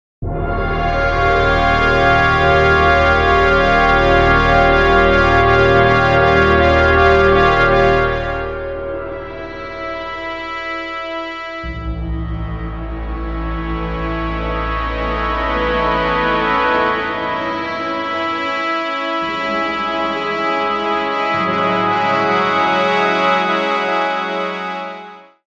Classical. Dramatic. Synth Pad.